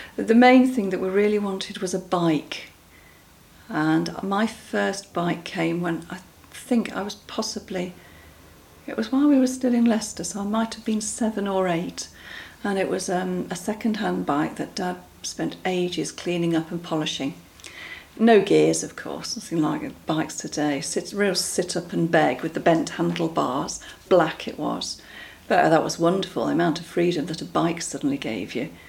16 sound clips (six sound effects and 10 spoken descriptions of toys); PDF of 6 bingo cards to print (1 has pictures to match purely the sound effects, 1 has pictures to match with all spoken descriptions, 4 are a mixture of sound effects and spoken words).
Bikes
Bike-sound-clip.mp3